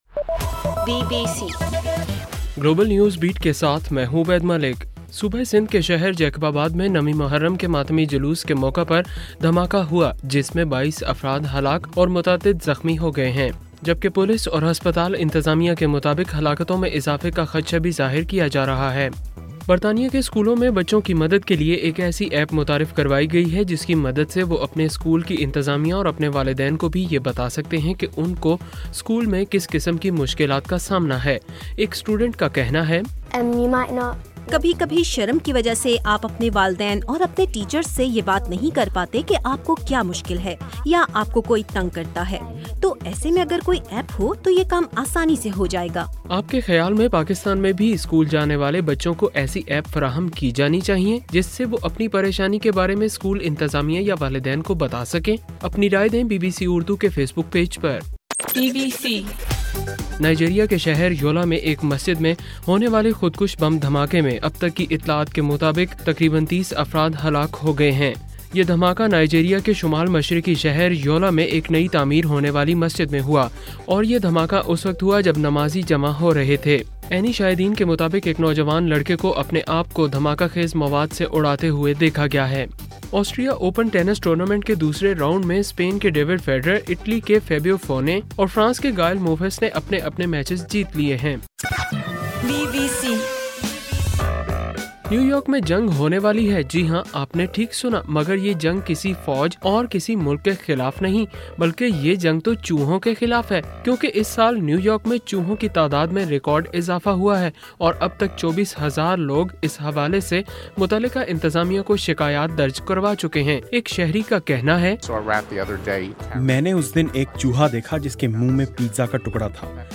اکتوبر 23: رات 12 بجے کا گلوبل نیوز بیٹ بُلیٹن